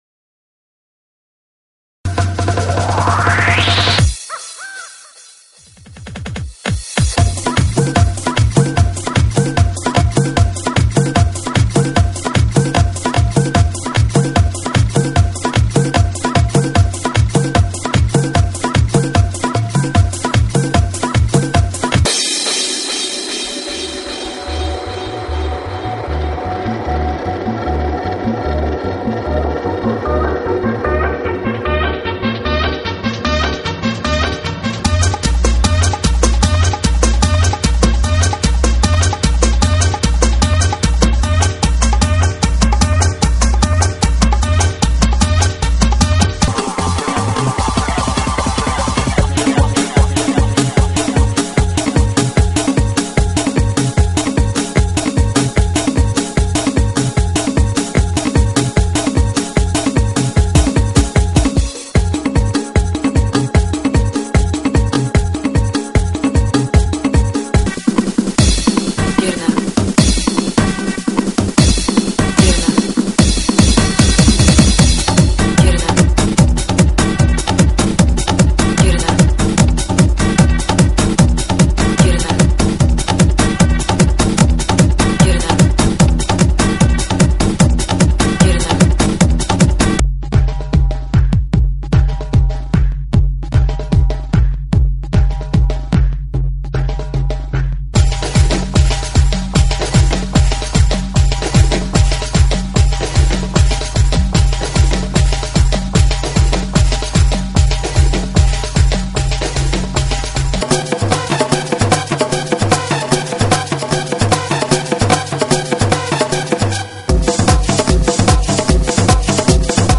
GENERO: TRIBAL – LATINO
TRIBAL LATINO,